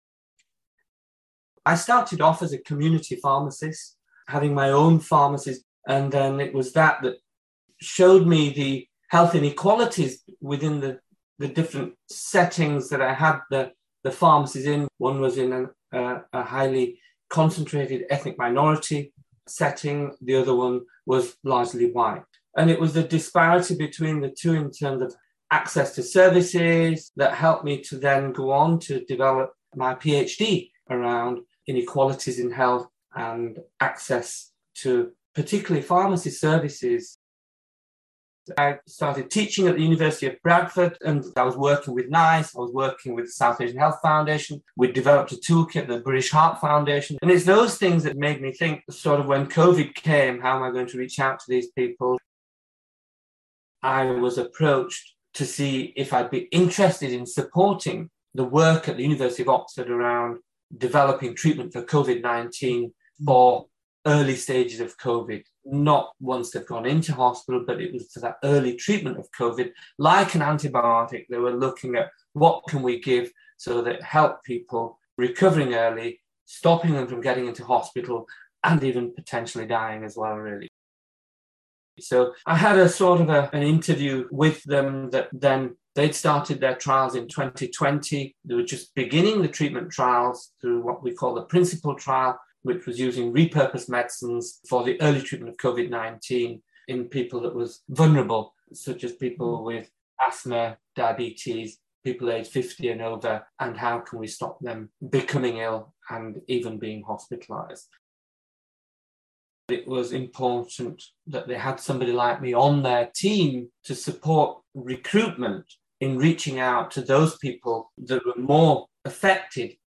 RCPharms Museum has a growing collection of oral history recordings where pharmacists past and present share their experiences in their own words.